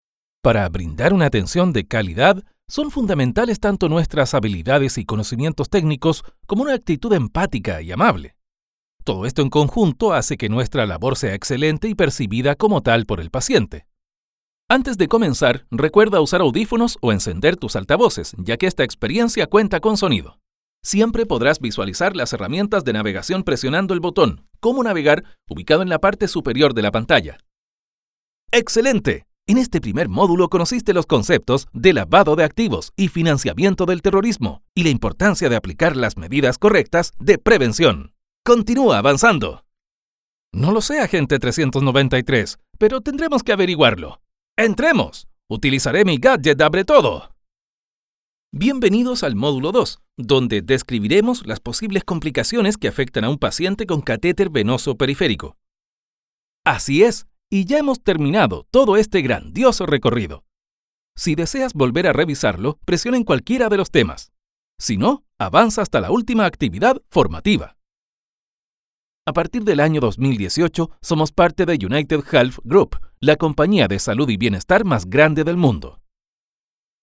Locución E-learning
Una narración cercana y natural o también puede ser jovial y motivadora que guíe al alumno a y refuerse los recursos del temario, incentivando a seguir.
• Sala Acústica para grabaciones limpias de ruidos.
demo-mix-elearning.mp3